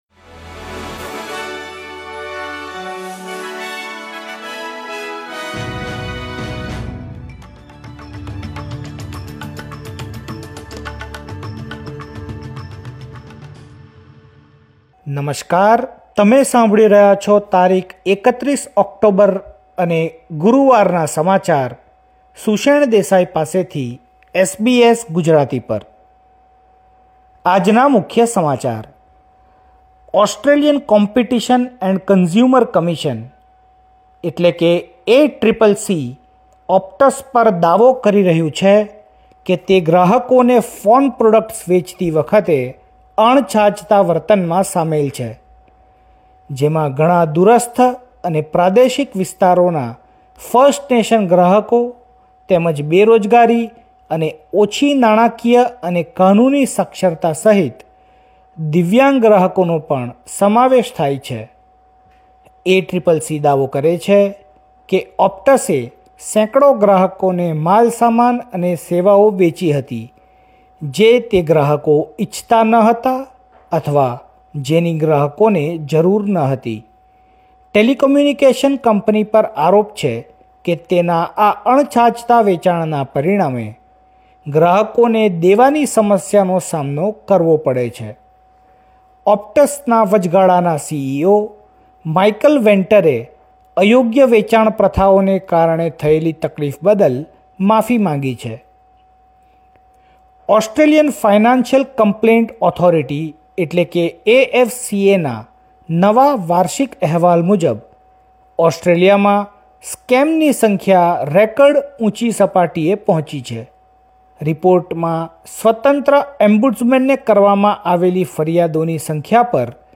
SBS Gujarati News Bulletin 31 October 2024